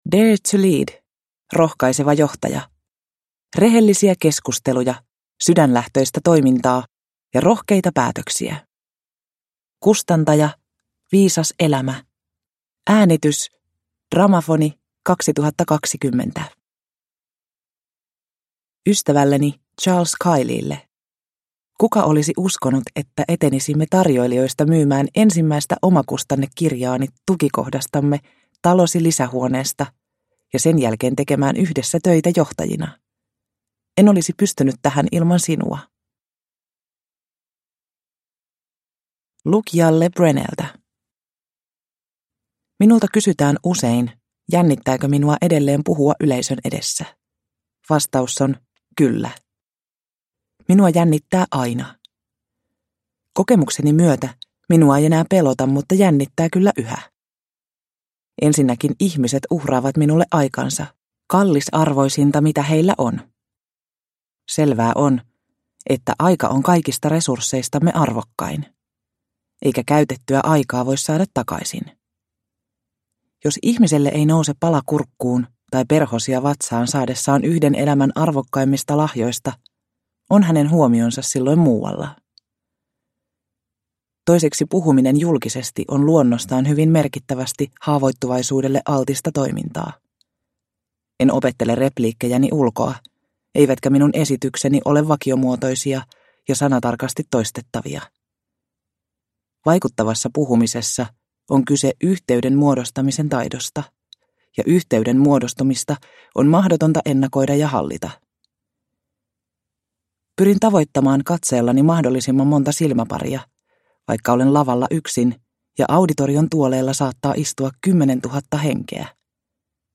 Rohkaiseva johtaja – Ljudbok – Laddas ner